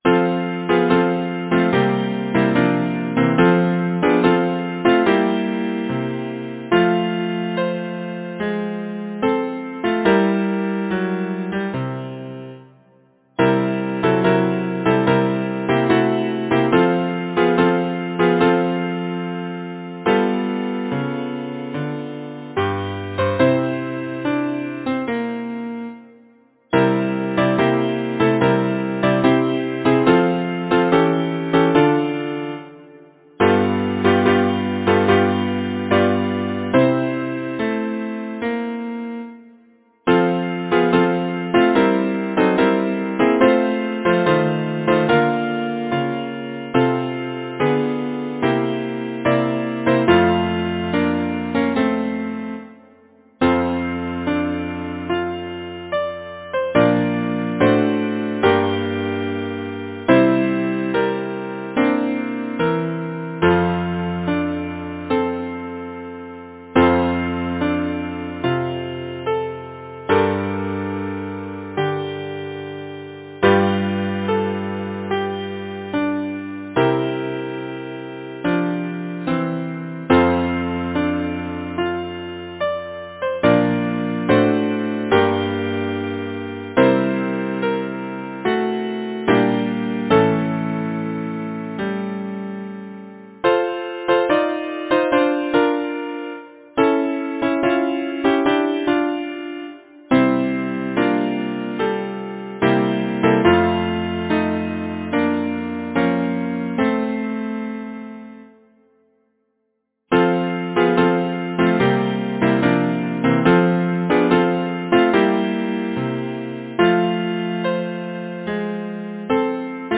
Title: Sleep, Dusky Babe Composer: Francis A. Clark Lyricist: Number of voices: 4vv Voicing: SATB, some divisi Genre: Secular, Partsong
Language: English Instruments: A cappella